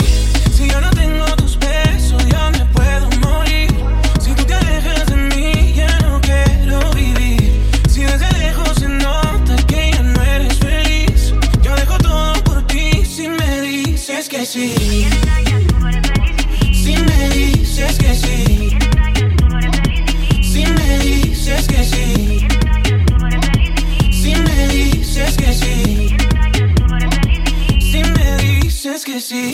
Genere: pop latin, urban latin, reggaeton, remix